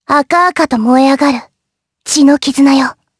Lewsia_A-Vox_Skill3_jp.wav